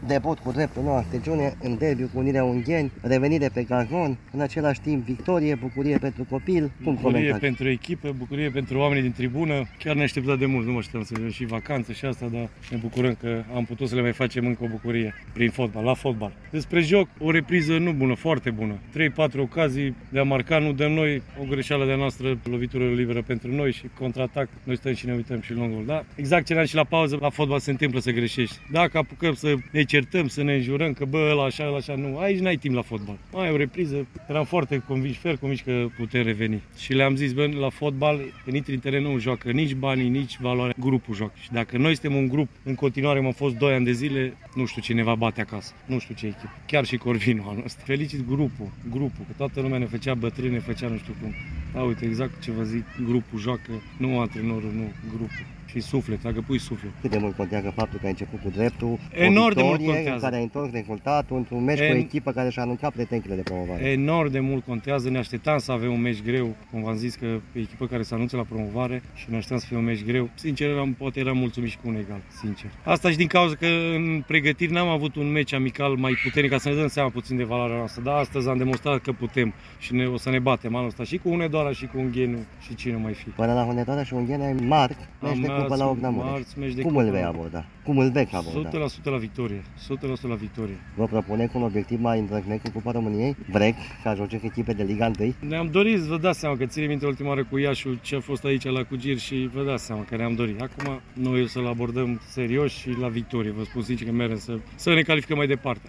Iată ce a declarat după partidă